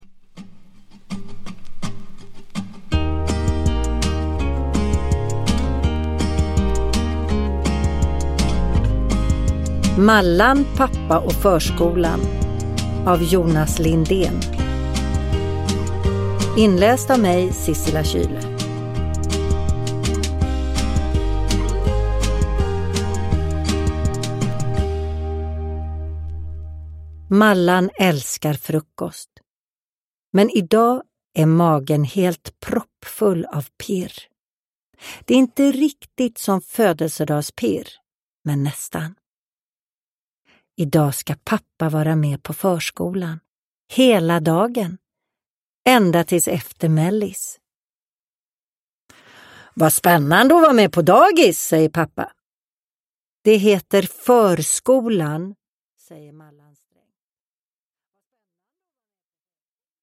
Uppläsare: Sissela Kyle